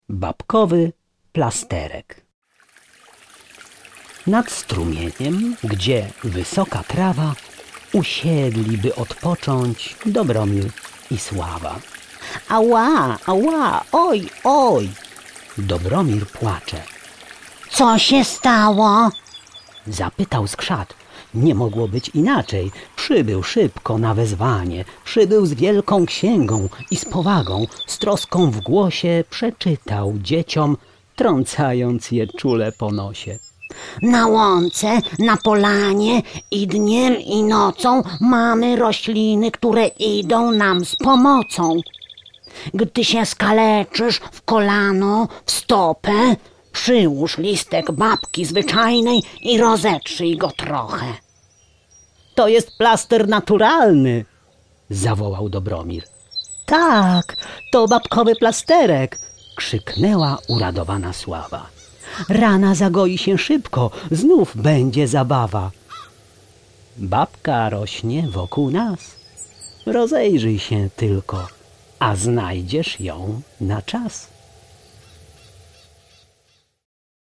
Sekrety ze Skrzaciej skrzyni - Cesarz Cezary - audiobook